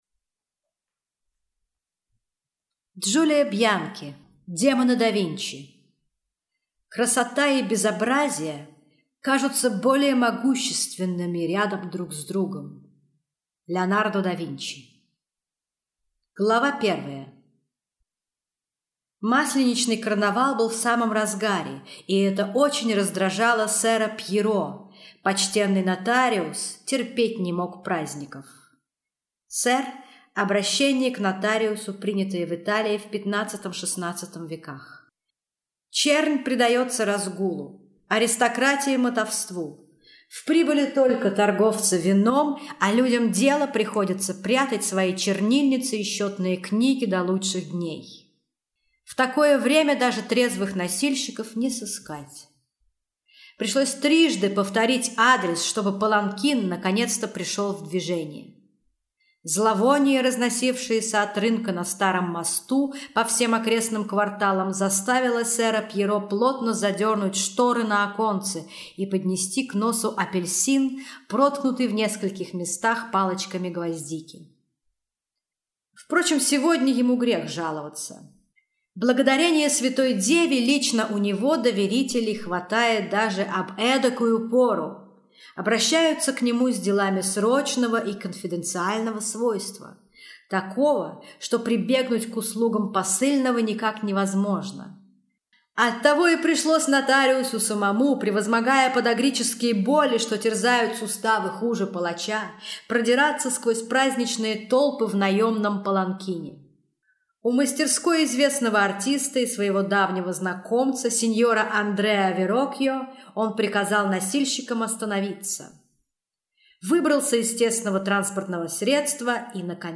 Аудиокнига Демоны да Винчи | Библиотека аудиокниг